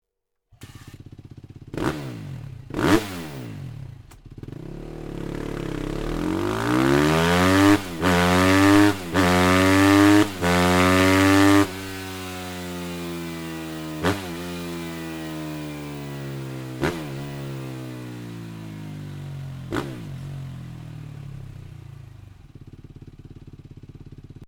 Sound Akrapovic Slip-On mit dB Killer